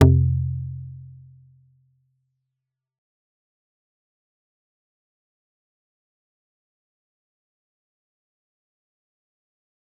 G_Kalimba-F2-f.wav